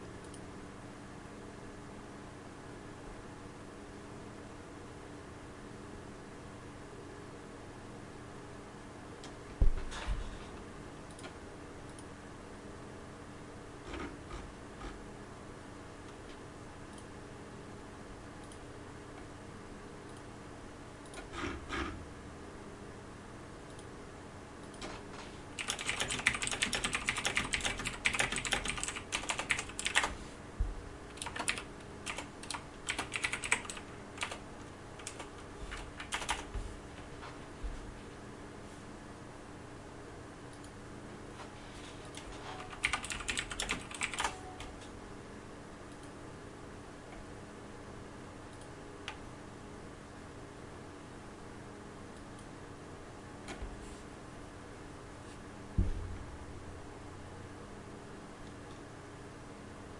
常规游戏女声样本 " 嗯，好大的......剑 中性的
标签： 中性 大什么的
声道立体声